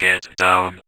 VVE1 Vocoder Phrases
VVE1 Vocoder Phrases 21.wav